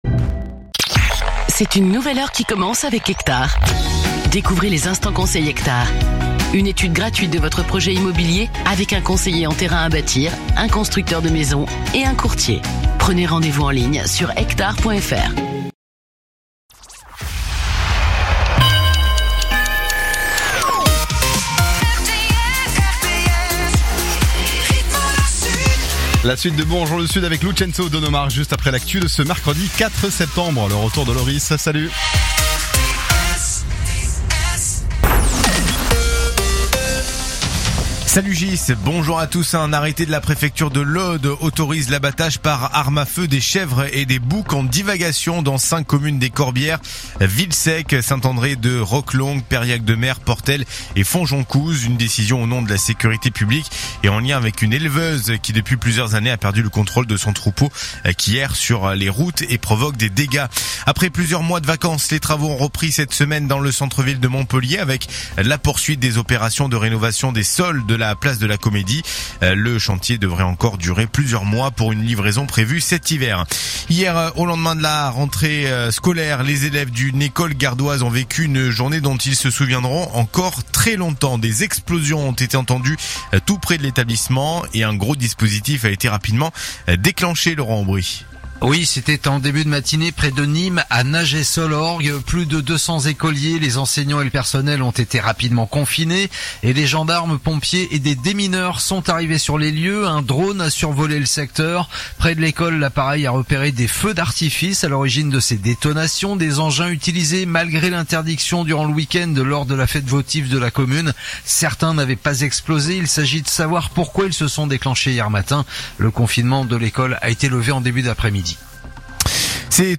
info_mtp_sete_beziers_131.mp3